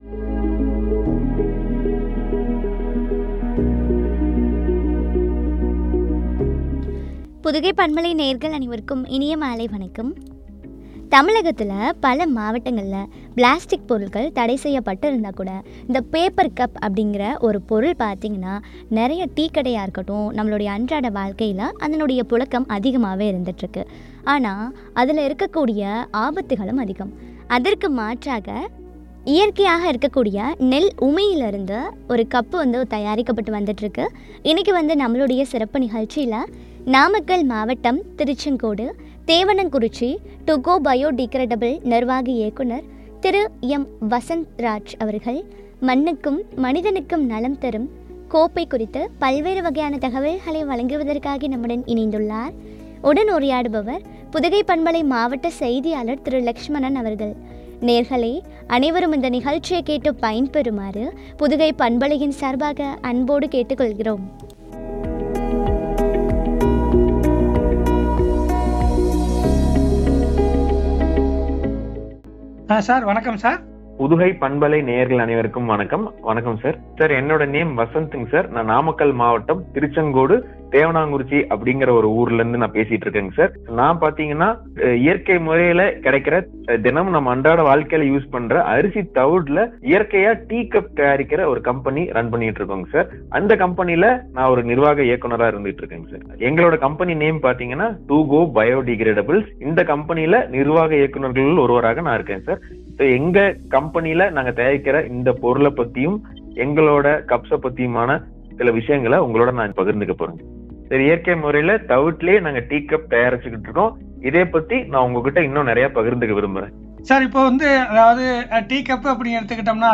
” மண்ணுக்கும் மனிதனுக்கும் நலம் தரும் கோப்பை ” குறித்து வழங்கிய உரையாடல்.